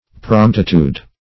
Promptitude \Prompt"i*tude\, n. [F., fr. L. promptitudo.